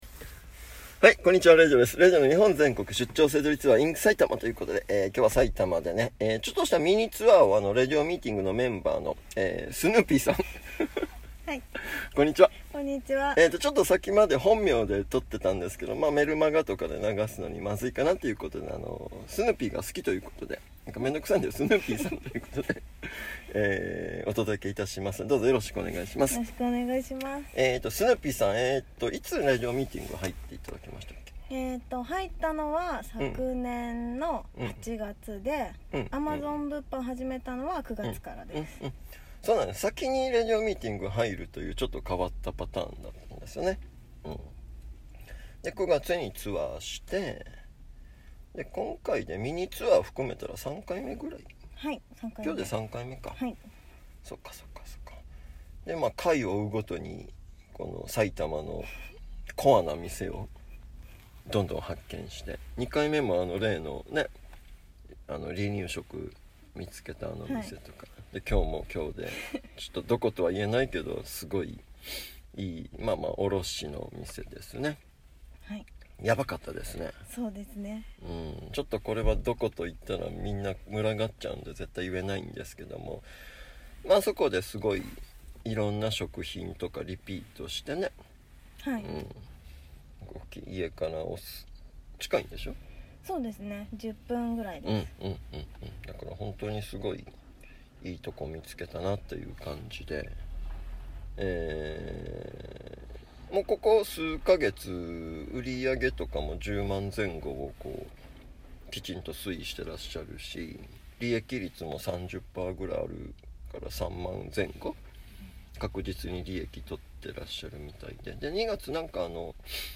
ジャンル: ボイスメモ